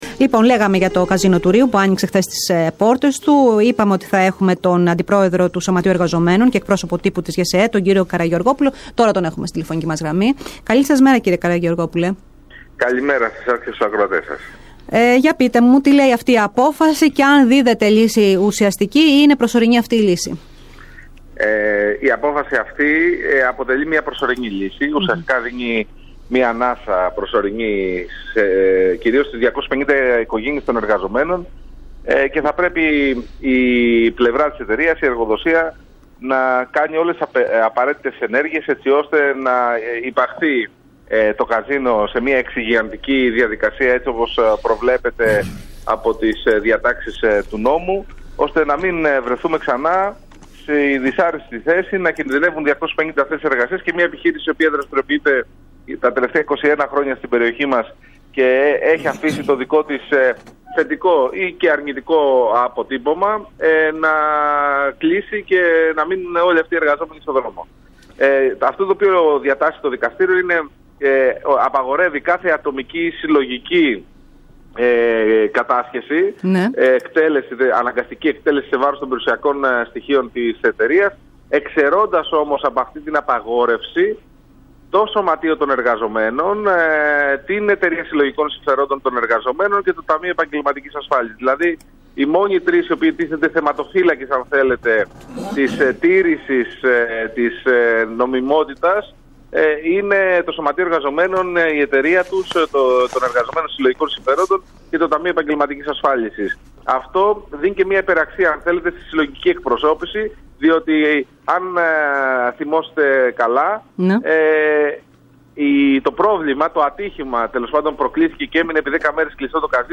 μιλώντας σήμερα στην ΕΡΤ ΠΑΤΡΑΣ και στην εκπομπή « Στον αέρα…μέρα παρά μέρα» δήλωσε ικανοποιημένος από την έκβαση της υπόθεσης.